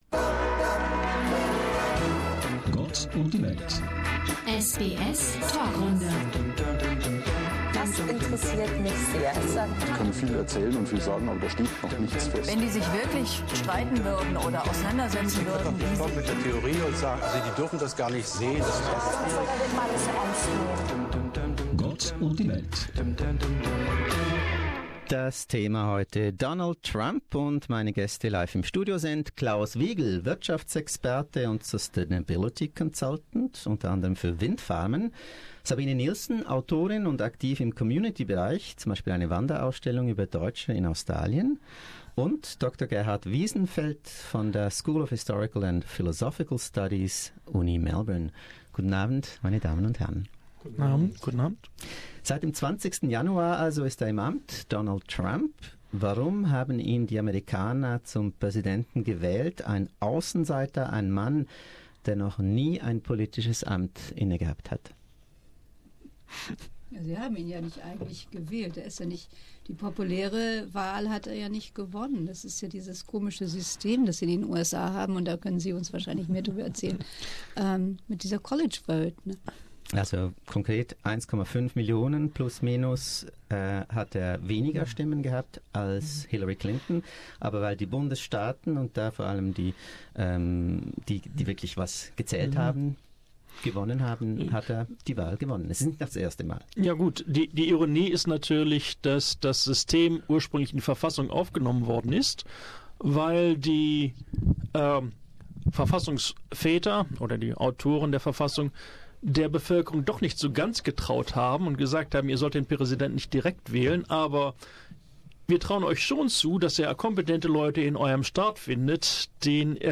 But why is the 45th U.S. President such a controversial figure? Why was he elected, and will his voters come to regret their decision to elevate him to the world's most powerful political office? For answers, listen to this lively SBS panel discussion.
Live im Studio